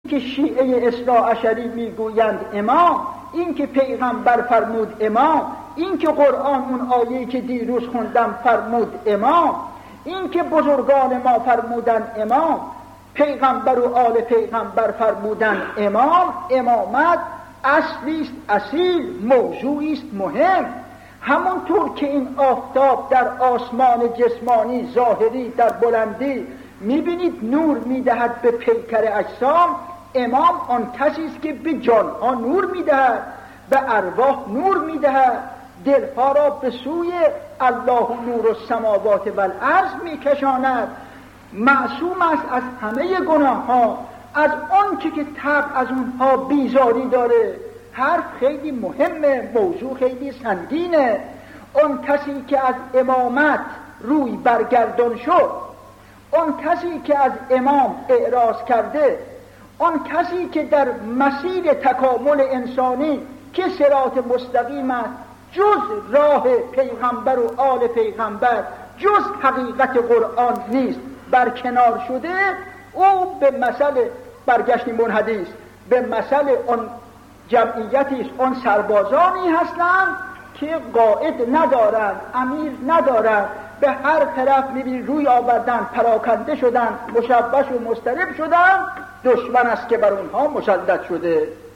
به گزارش خبرگزاری حوزه، مرحوم علامه حسن زاده آملی در یکی از سخنرانی های خود به موضوع «امامت؛ محور هدایت» اشاره کرده‌اند که تقدیم شما فرهیختگان می شود.